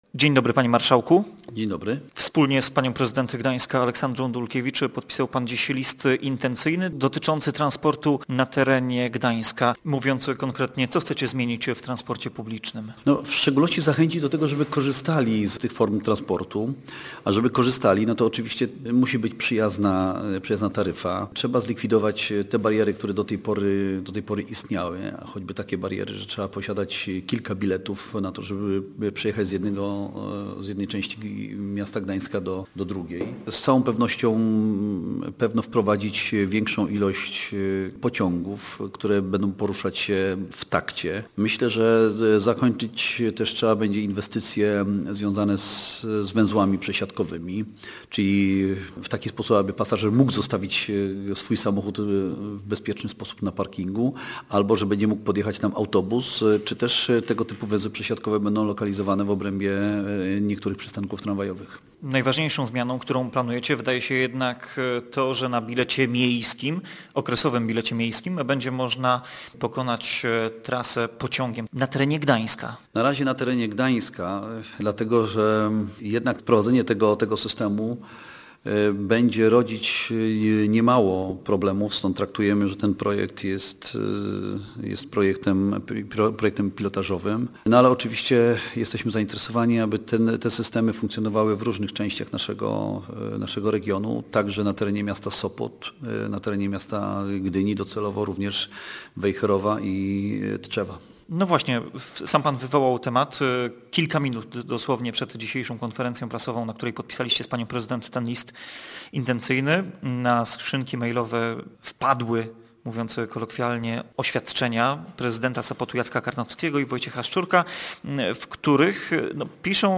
Wprowadzenie wspólnych biletów okresowych za 99 złotych zapowiedzieli w piątek na konferencji prasowej prezydent Gdańska Aleksandra Dulkiewicz i marszałek województwa pomorskiego Mieczysław Struk, który był Gościem Dnia Radia Gdańsk po 17:00.